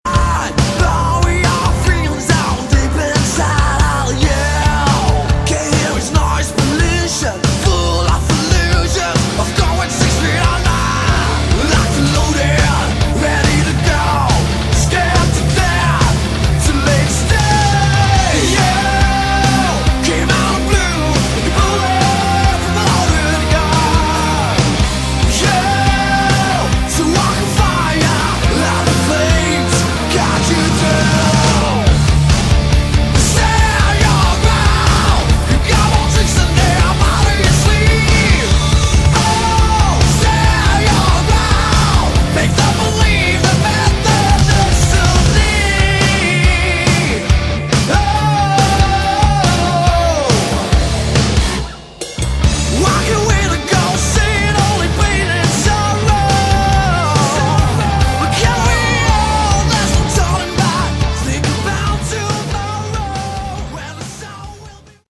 Category: Hard Rock
I like the more dangerous, youth gone wild sleazy edge.